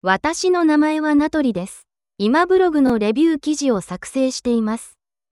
テキストウインドウはそのままで、別の音声タイプを指定して生成ボタンクリックで女性Verの音声の完成です
いい感じで性別が切り替わってますね
リカ女性.mp3